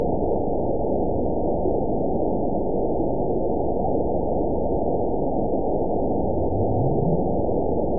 event 919850 date 01/26/24 time 02:16:52 GMT (1 year, 11 months ago) score 9.54 location TSS-AB02 detected by nrw target species NRW annotations +NRW Spectrogram: Frequency (kHz) vs. Time (s) audio not available .wav